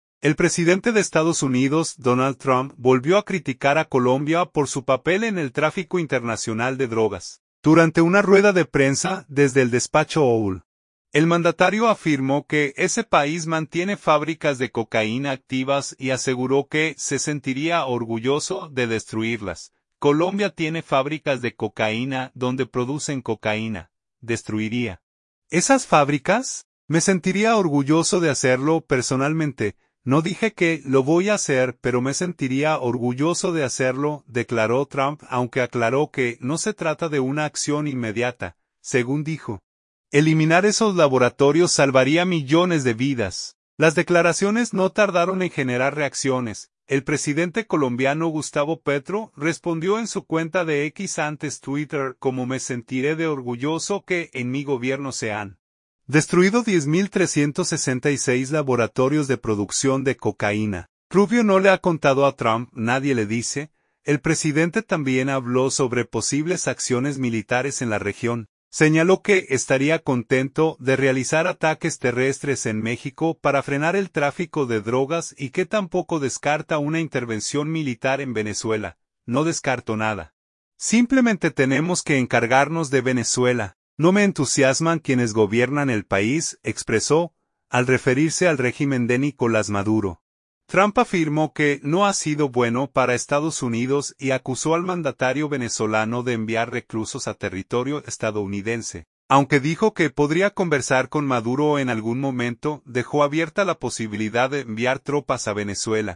El presidente de Estados Unidos, Donald Trump, volvió a criticar a Colombia por su papel en el tráfico internacional de drogas. Durante una rueda de prensa desde el Despacho Oval, el mandatario afirmó que ese país “mantiene fábricas de cocaína activas” y aseguró que “se sentiría orgulloso” de destruirlas.